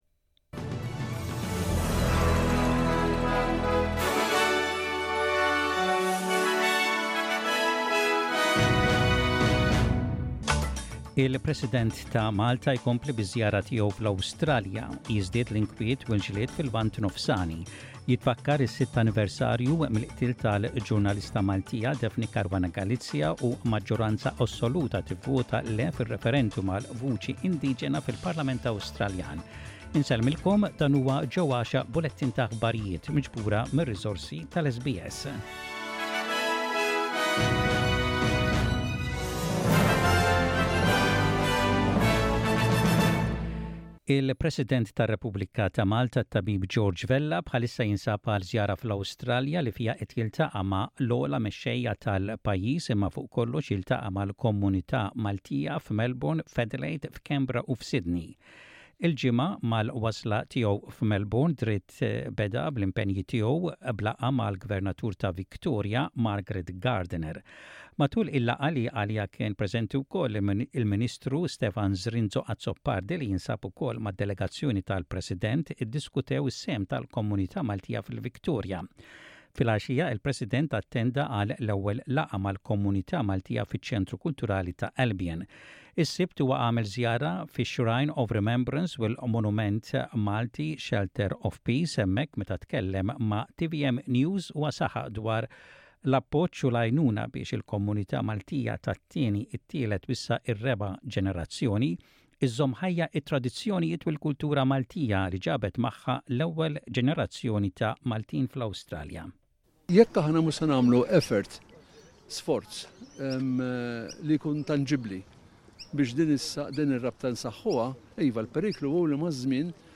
SBS Radio | Maltese News: 17.10.23